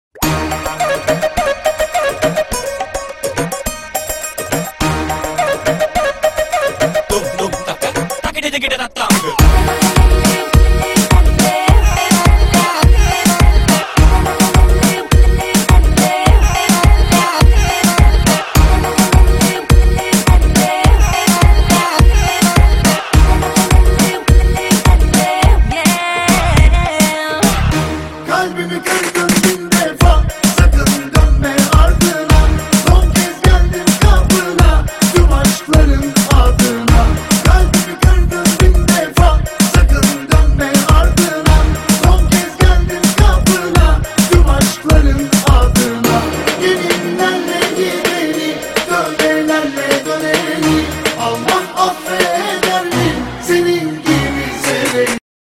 آهنگ شاد و نوستالژیک ترکی: